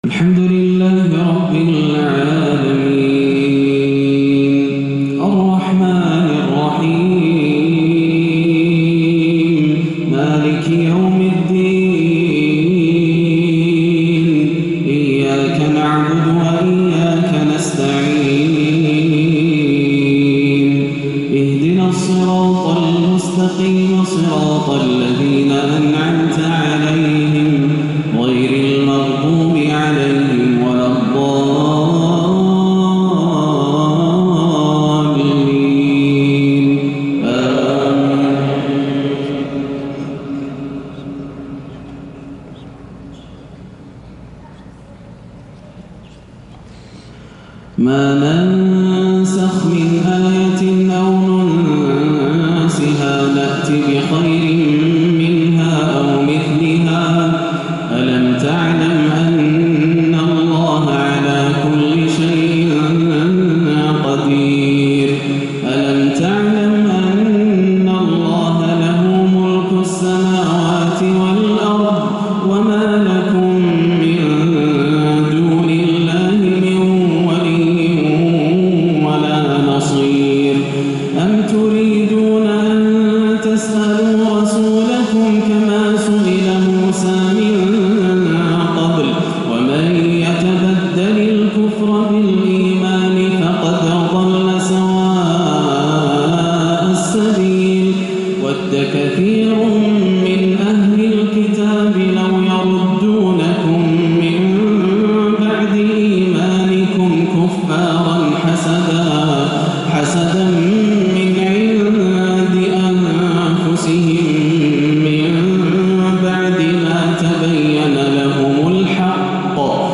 عشاء السبت 30 رجب 1437هـ من سورة البقرة 106-117 > عام 1437 > الفروض - تلاوات ياسر الدوسري